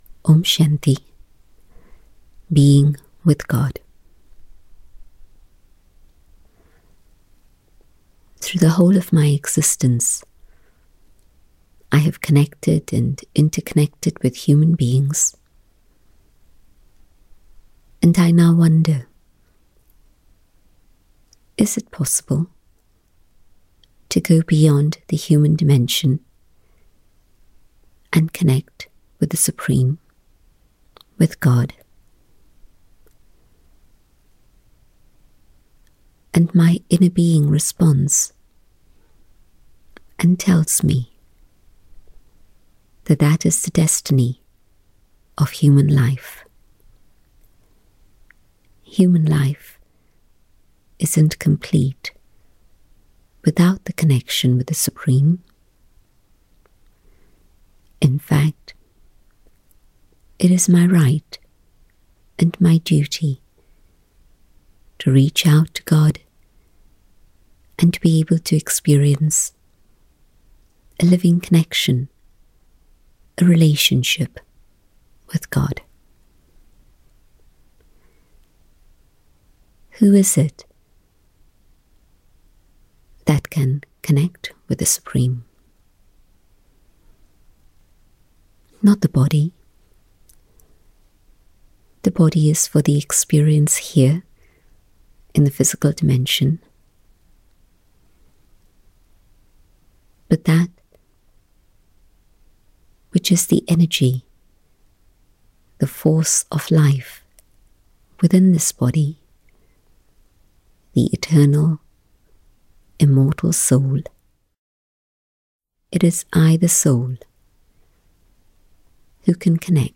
Downloadable Meditations